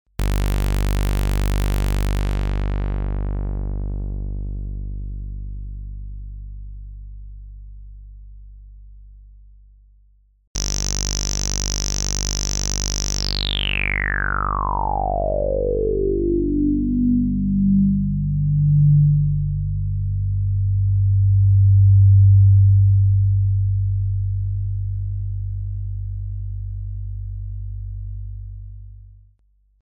Pic 10: scetch of a lowpass vcf (filter) with cutoff frequency Fc and resonans/q. C lick to hear a sawtooth wave applied to a lp filter 1st witout any res., then with massive res.
Notice how the resonant filter "picks out" the last harmonics quite noticeably.
filteredsaw2.mp3